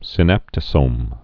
(sĭ-năptə-sōm)